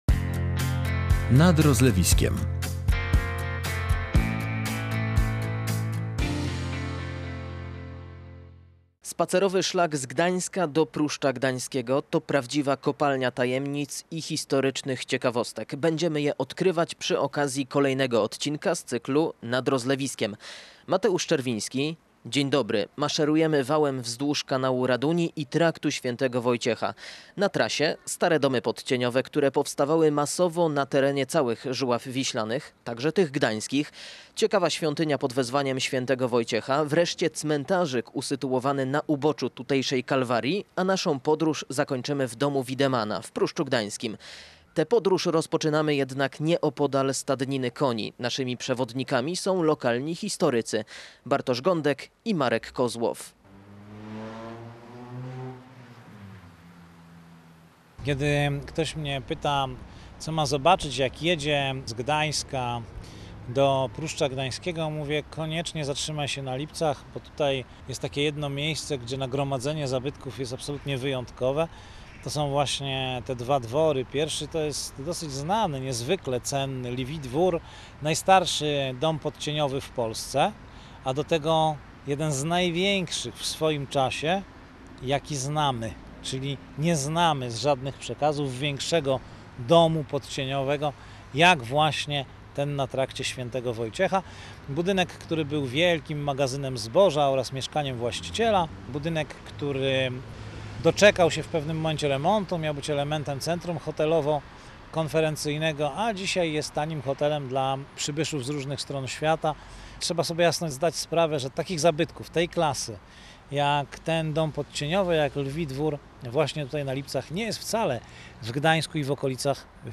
Maszerujemy wałem wzdłuż kanału Raduni i Traktu św. Wojciecha.